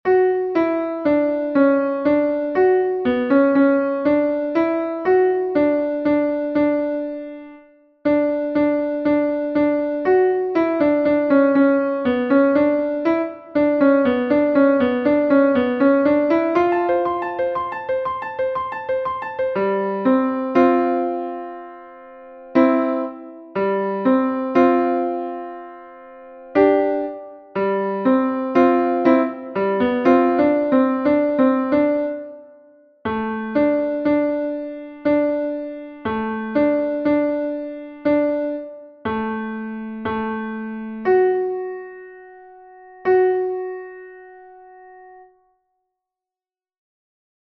These are loud. Turn your sound down before starting a recording.
Measures 126 to the end. Tenor only. Slow.
alleluia_by_basler-126-end-tenor.mp3